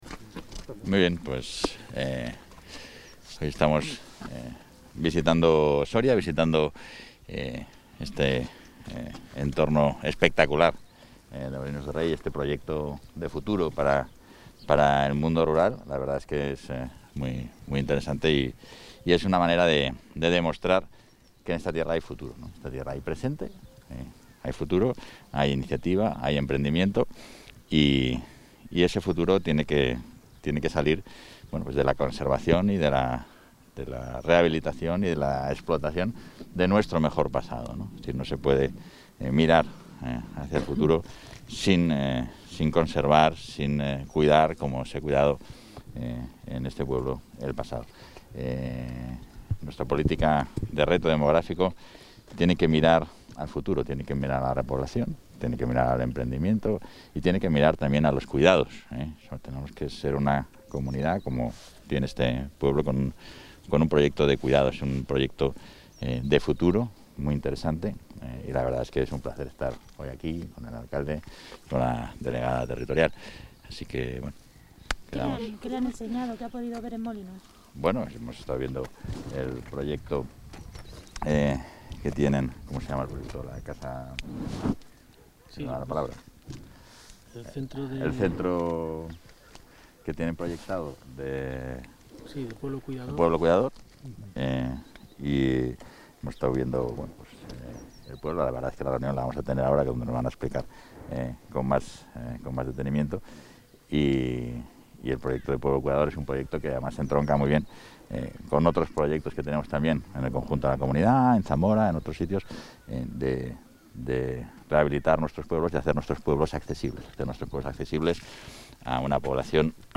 Material audiovisual de la visita de Francisco Igea a Molinos de Duero (Soria)
Audio vicepresidente.